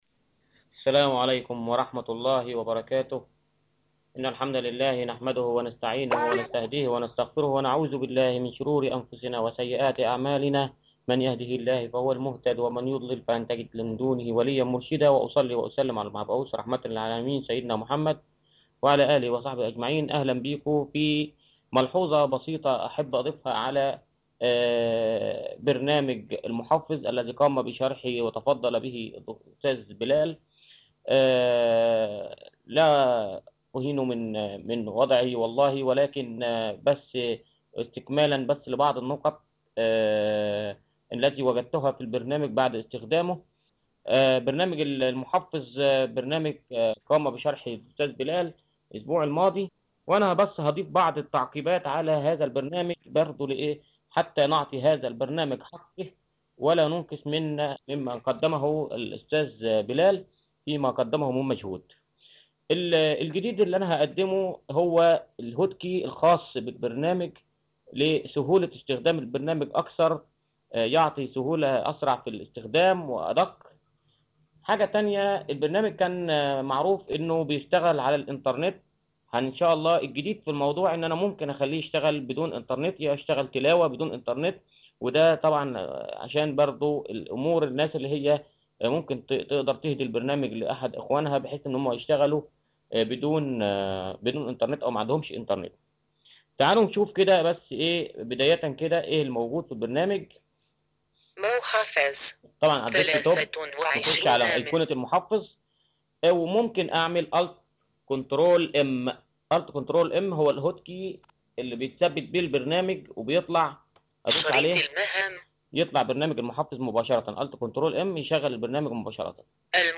نحمد الله بأنه تم الاستفادة من برنامج المحفز لتلاوة القرآن الكريم من قبل المكفوفين وخاصة لأنه متوافق مع برمجيات القراءة الآلية للمكفوفين وتوفر اختصارات لوحة المفاتيح الكثيرة لأوامر البرنامج. هذا شرح خاص للمكفوفين